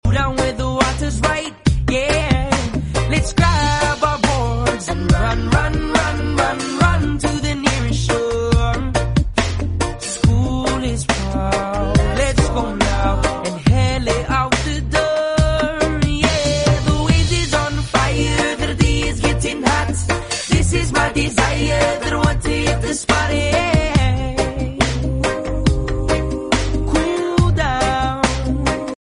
Bisaya song